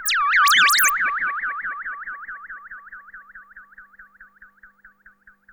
Theremin_FX_05.wav